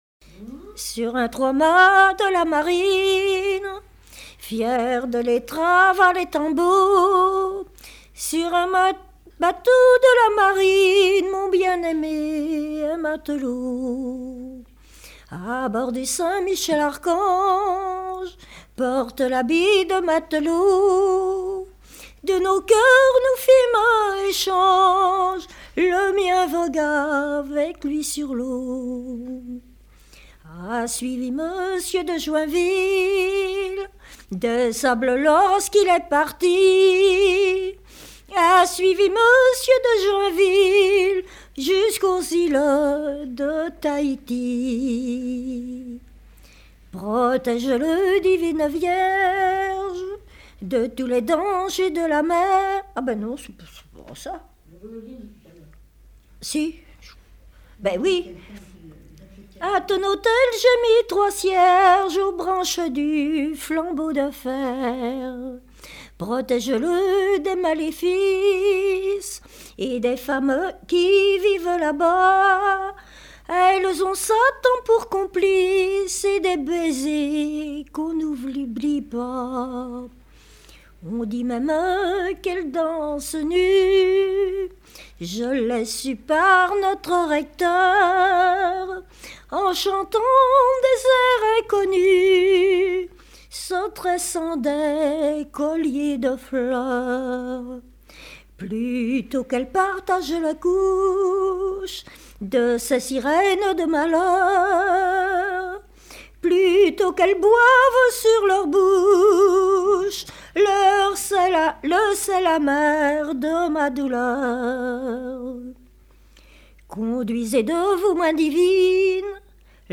Genre strophique
Pièce musicale inédite